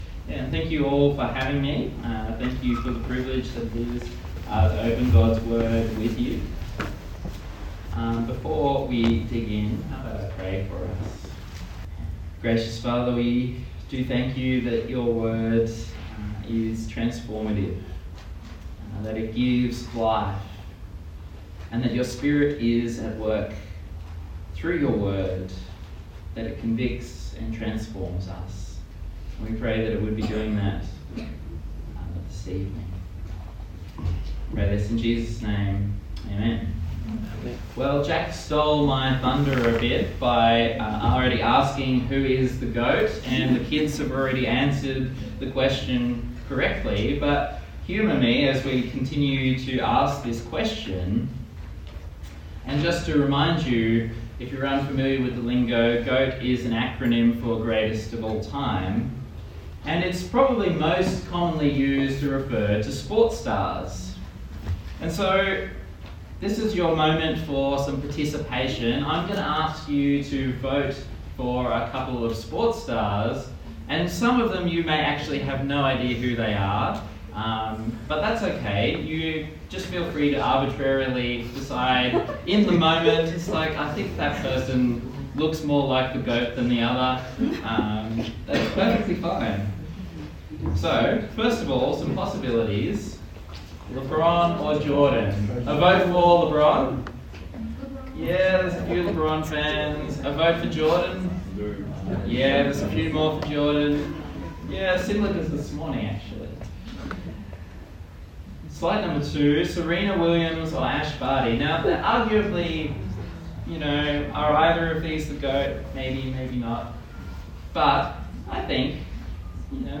Luke Passage: Luke 9:37-50 Service Type: Sunday Service